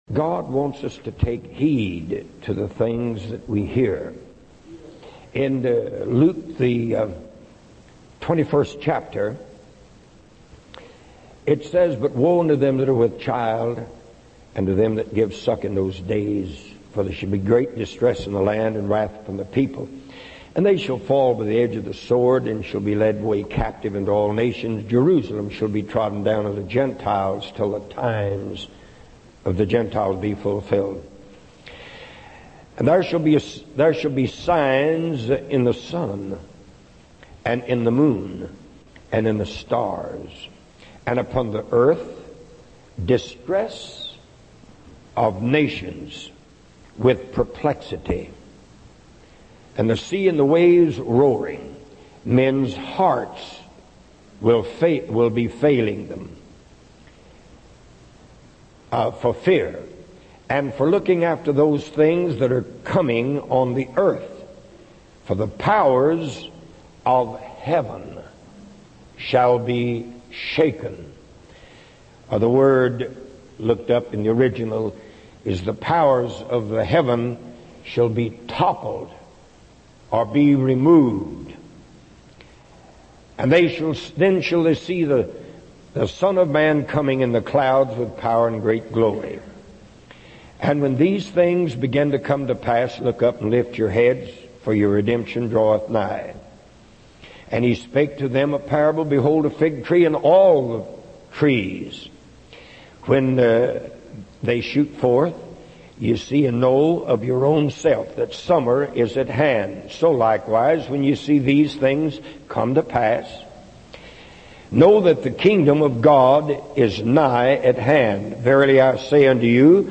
In this sermon, the speaker discusses the signs and events that Jesus foretold would happen before the coming of the kingdom of God. These signs include perplexities of nations, wars, famines, and pestilence.